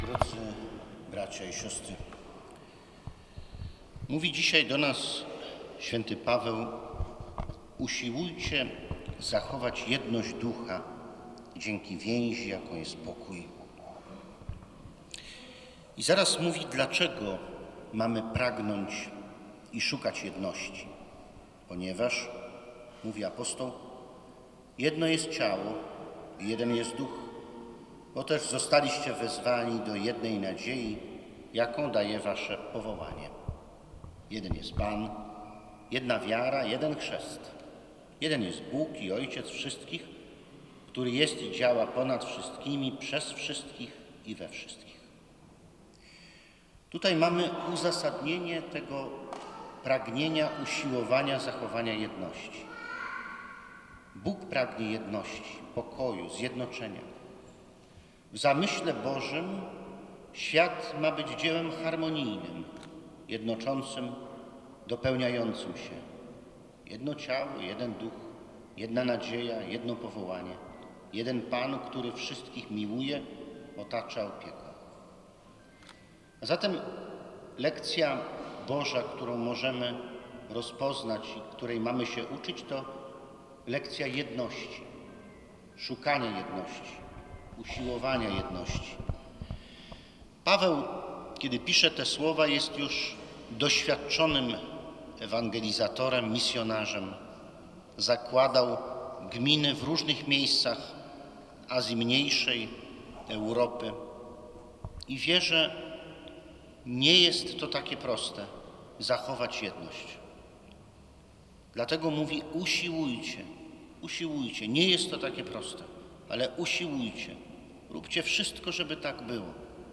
W Sanktuarium na warszawskim Gocławiu po raz siódmy odbył się Dzień Seniora i Chorego Diecezji Warszawsko-Praskiej. Eucharystię w intencji cierpiących odprawił biskup pomocniczy Jacek Grzybowski.
calahomilia.mp3